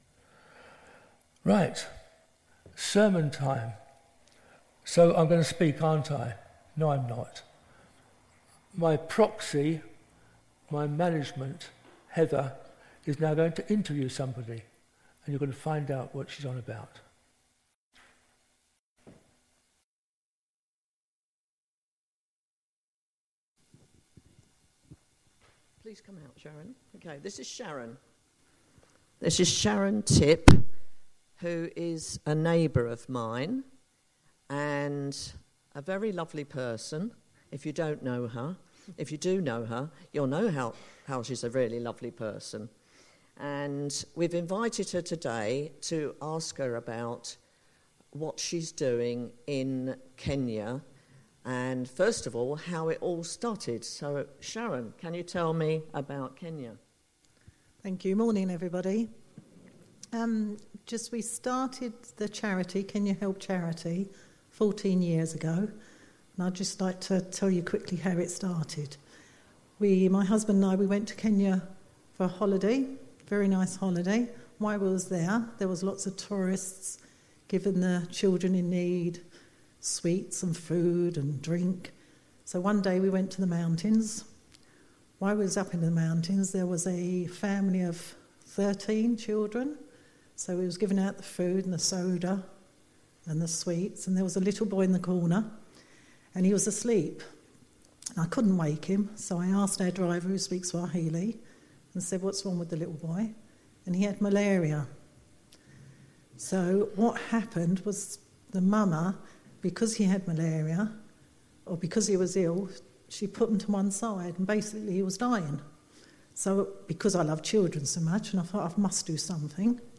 The sermon is also available as an audio file.
Ephesians 4:25-5:2 Service Type: Sunday Morning This morning’s service was led by our supernumerary minister